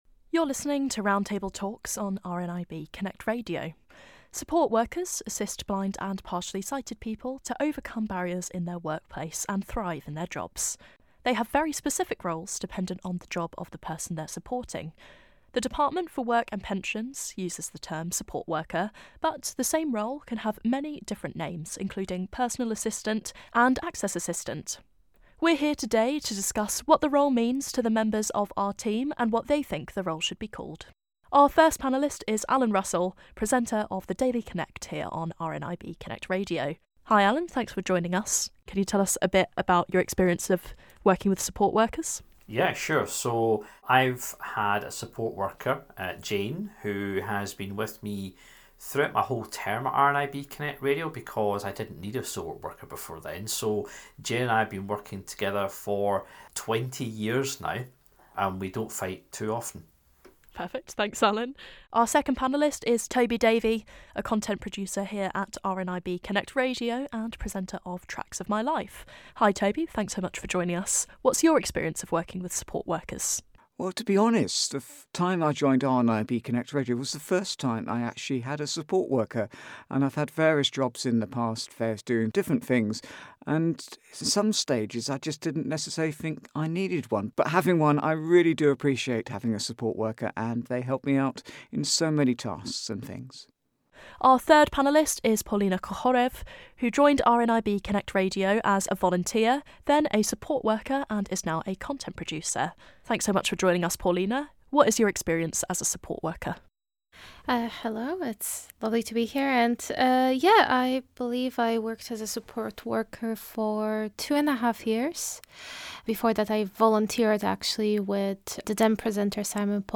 Support Worker Vs Access Assistant - Roundtable
How do blind and partially sighted people and their support workers feel about their work? We gathered four members of the RNIB Connect Radio Team to discuss.